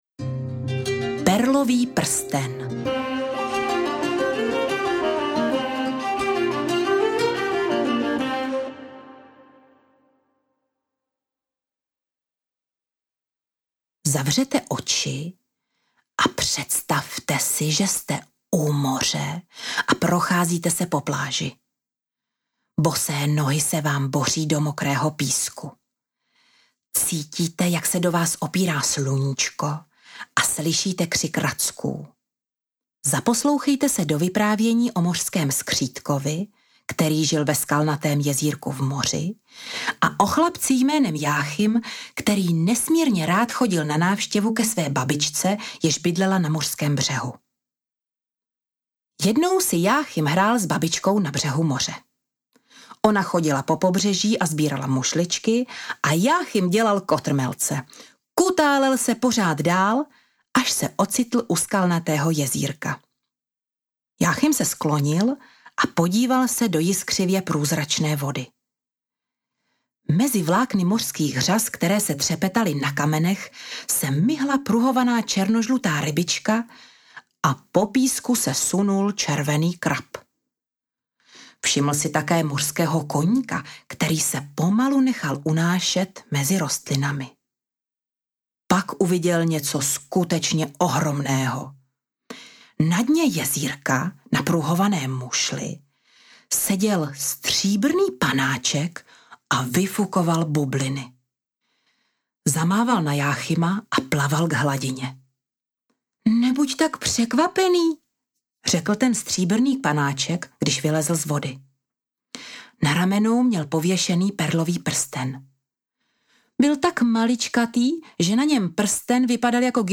Vílí pohádky na dobrou noc audiokniha
Ukázka z knihy